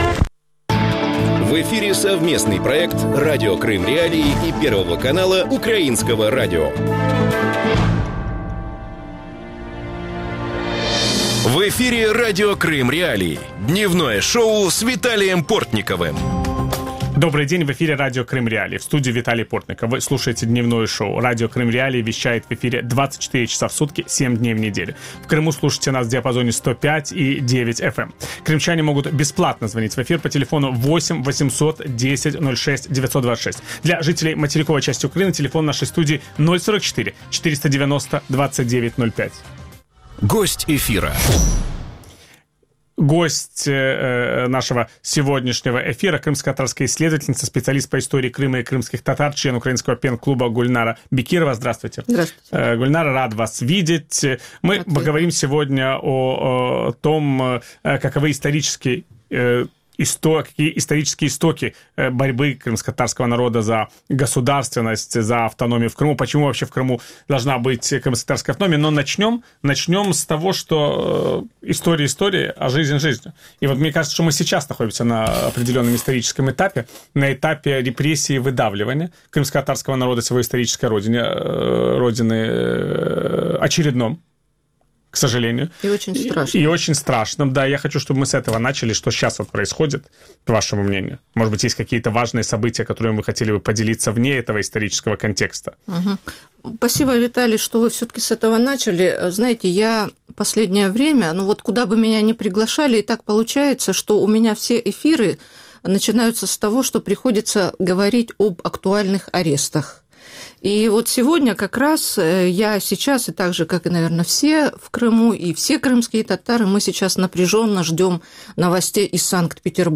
Ведущий – Виталий Портников.